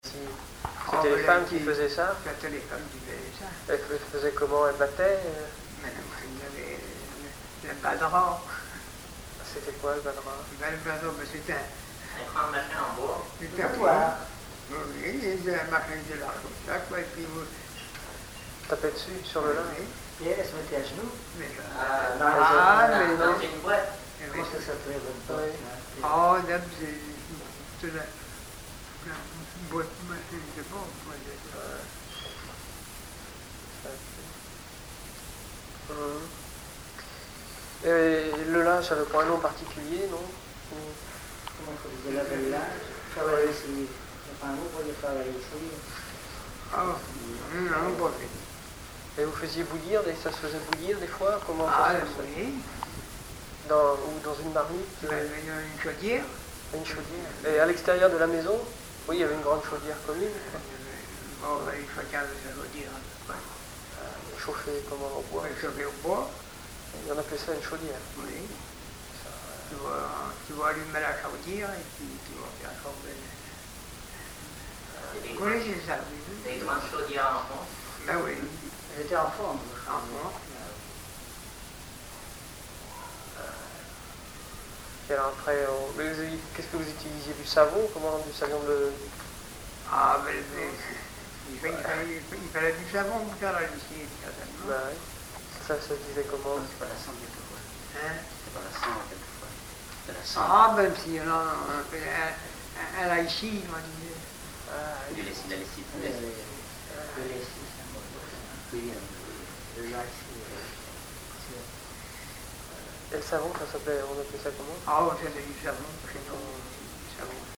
Enquête sur le patois maraîchin par des étudiants
Catégorie Témoignage